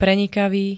Zvukové nahrávky niektorých slov
ux3q-prenikavy.ogg